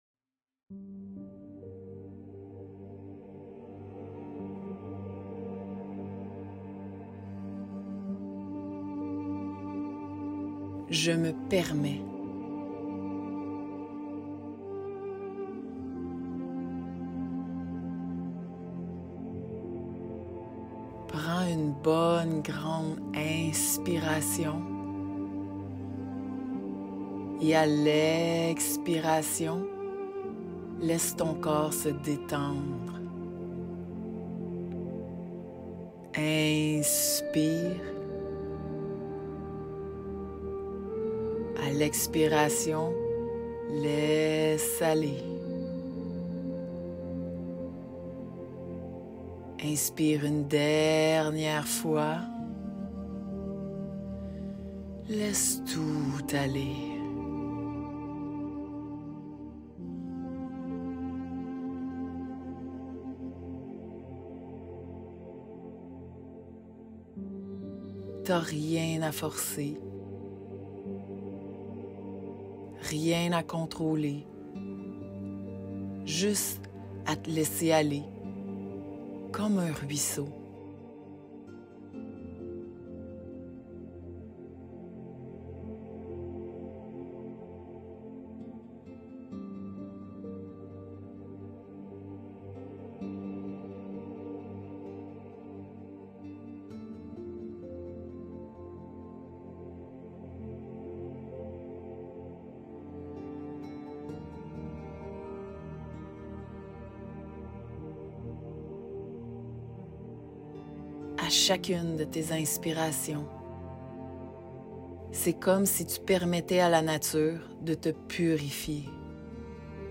Méditations guidées
Écriture, enregistrement, montage, test… rien n’est laissé au hasard.